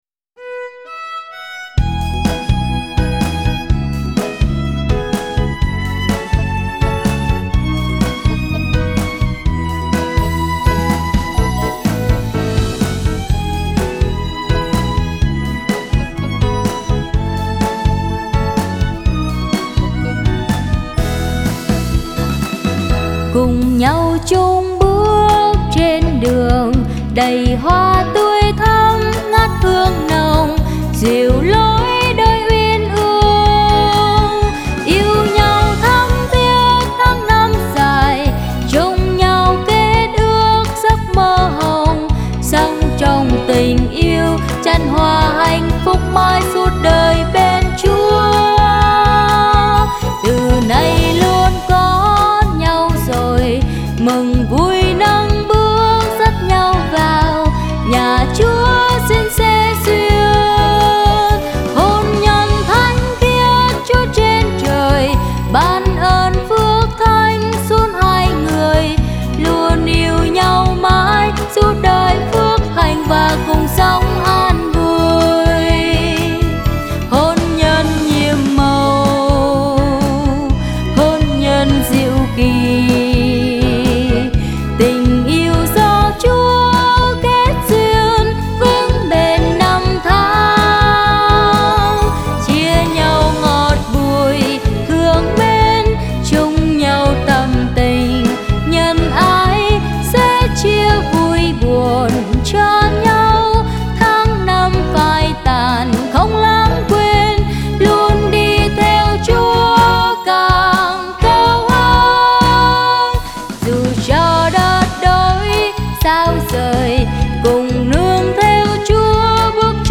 Nhạc Sáng Tác Mới